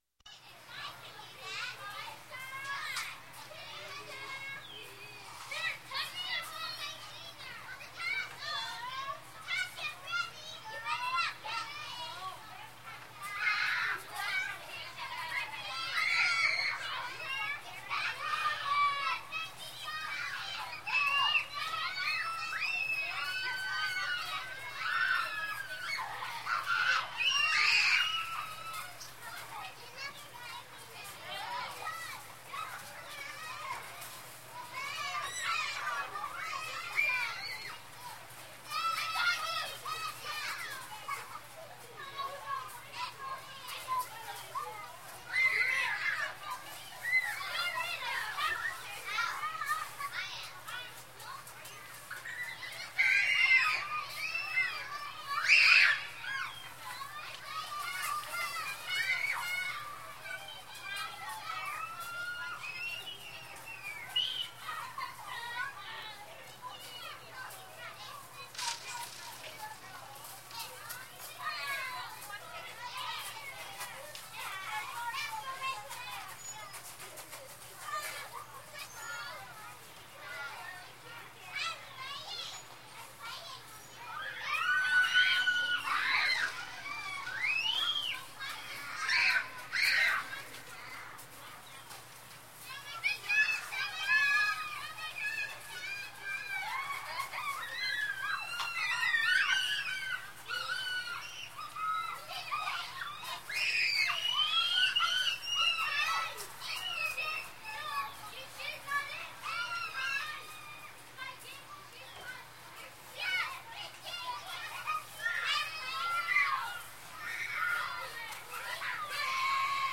На этой странице собраны звуки, характерные для детского лагеря: смех ребят, шум игр на свежем воздухе, вечерние посиделки с гитарой и другие атмосферные моменты.
еще дети